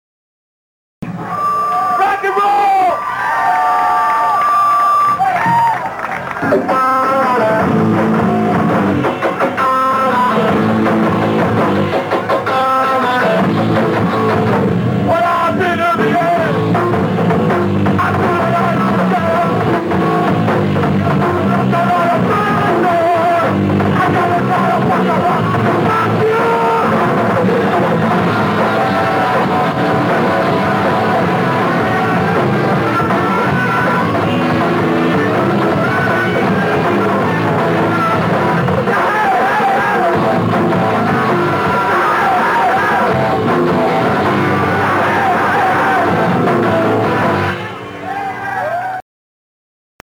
Short Live Video